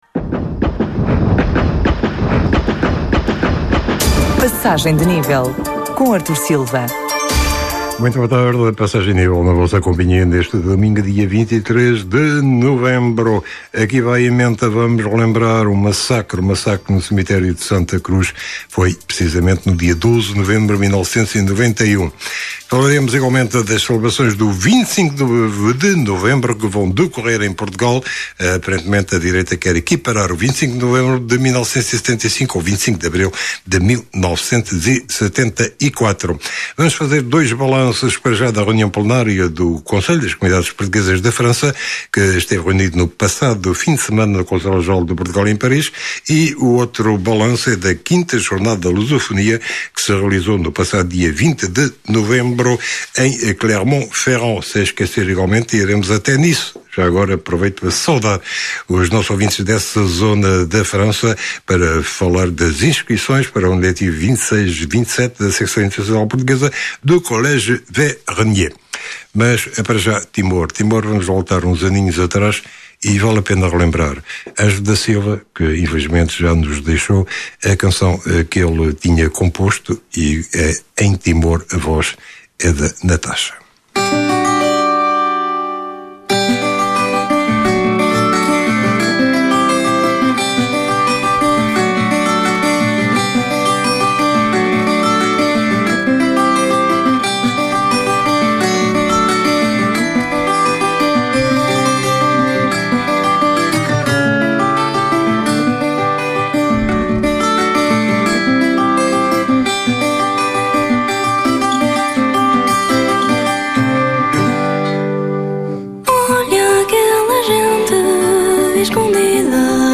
Entrevista. Emídio Sousa, Secretário de Estado das Comunidades Portuguesas